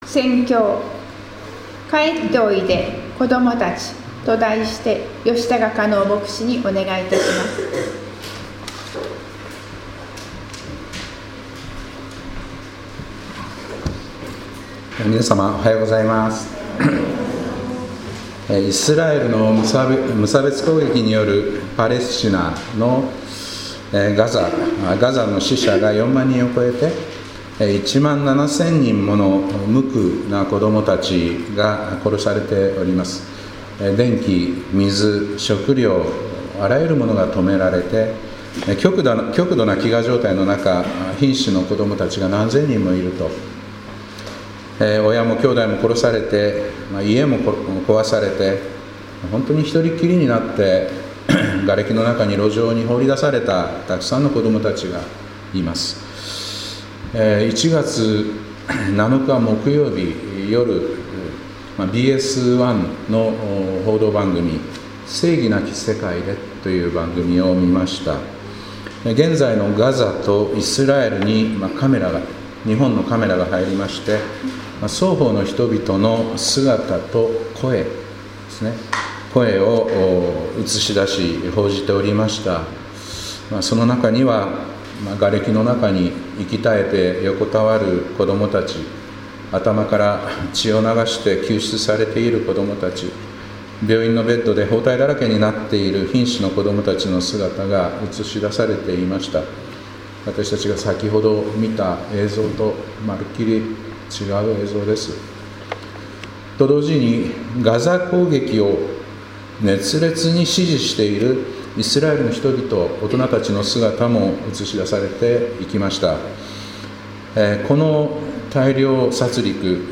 2024年11月10日礼拝「帰っておいで、子どもたち」